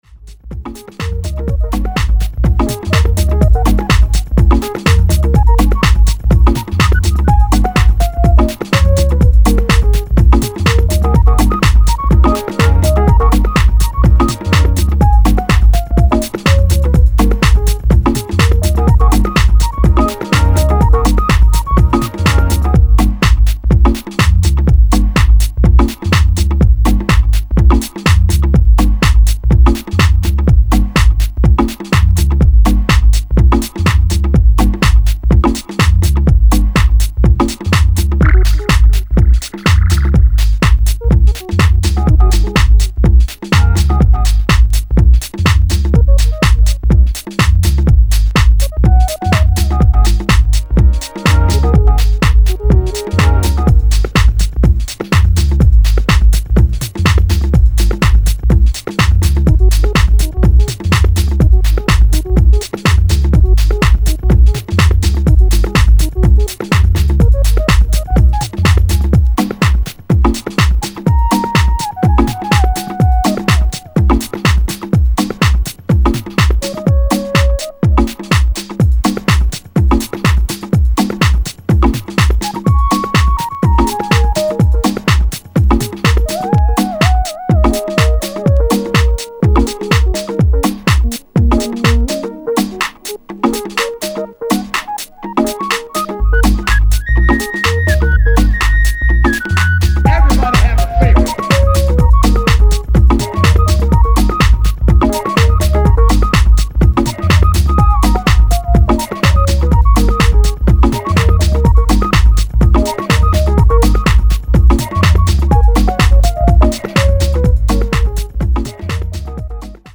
supplier of essential dance music
trio
jazzy and quirky House grooves